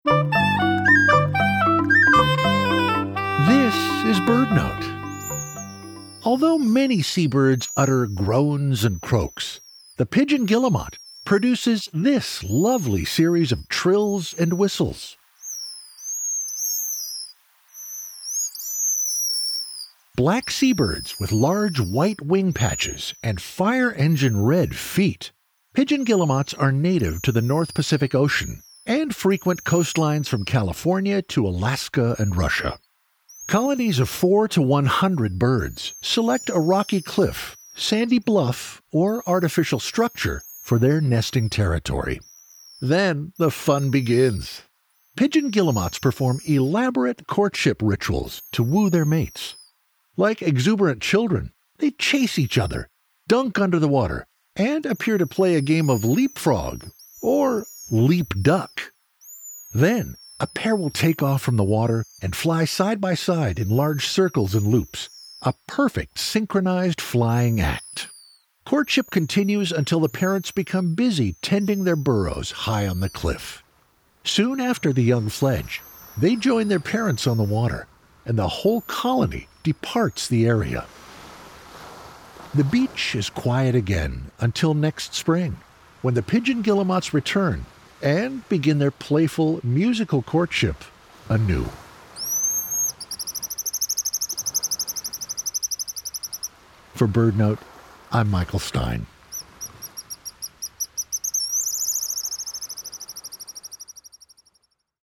Although many seabirds utter groans and croaks, the Pigeon Guillemot produces a lovely series of trills and whistles. As part of their courtship, they fly side by side in large circles and loops, a perfectly synchronized flying act.